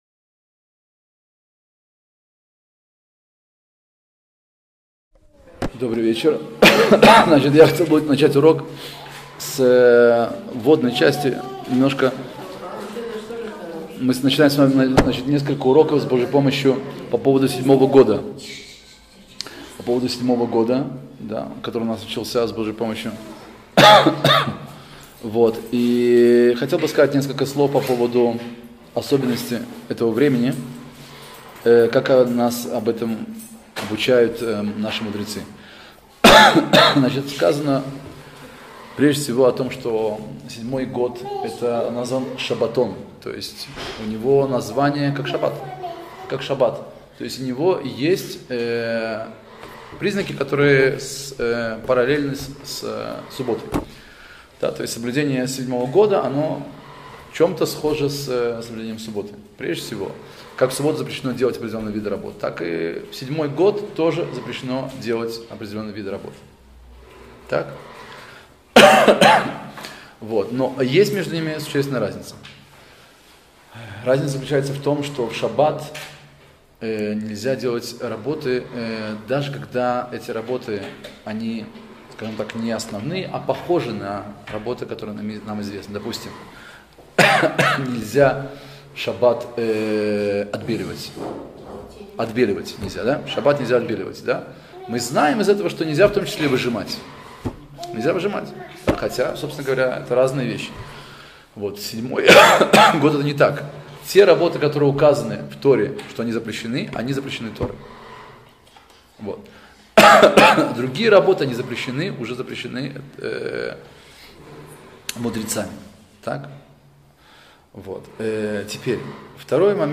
Законы Седьмого года – Урок 1, Введение.